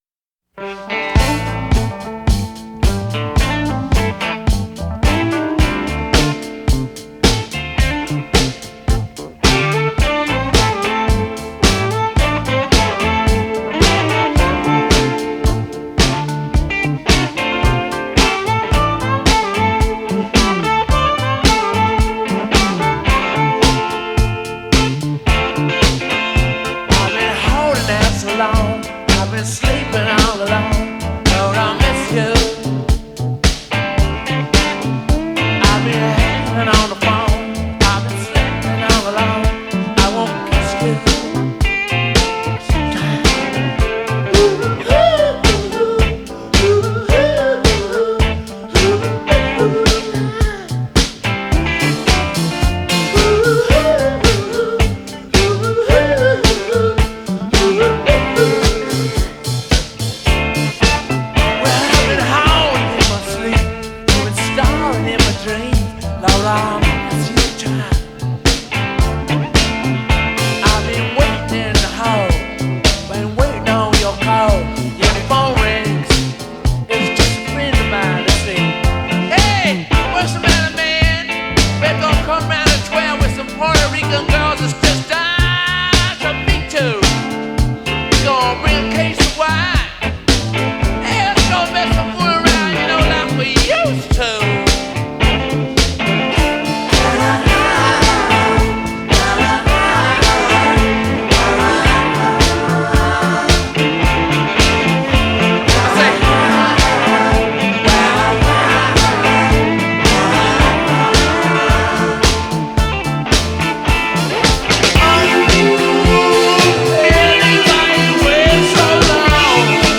hi hat ‘n four-to-the-floor disco beat
slashing, fluid A minors
propulsive, trampolining, head-nodder of a bassline
Sucking In The Seventies swagger